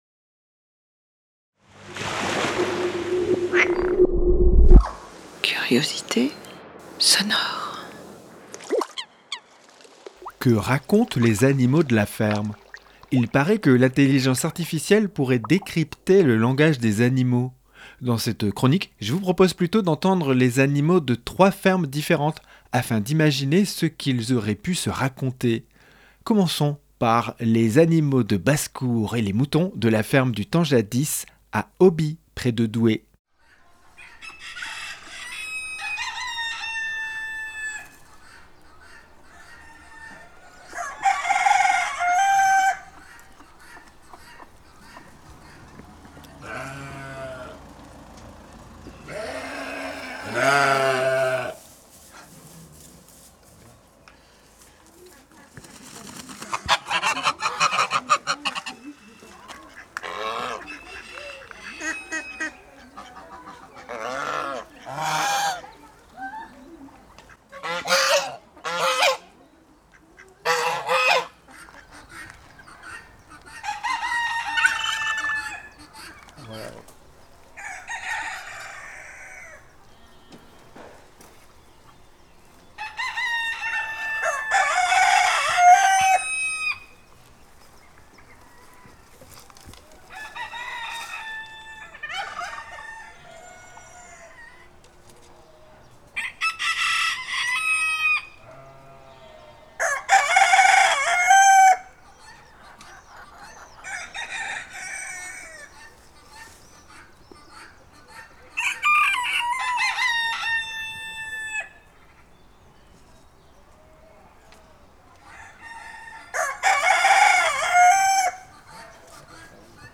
Dans cette chronique, je vous propose plutôt d’entendre les animaux de 3 fermes différentes afin d’imaginer ce qu’ils auraient pu se raconter.
• Les animaux de basse-cour et les moutons de la ferme du temps jadis à Auby (59)
• Les chevrettes de la chèvrerie des 2 villages à Esquerchin (59)
• Les veaux d’une ferme du village de Pelves (62)